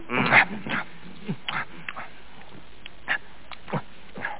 SFX饿了很久吃饭的声音音效下载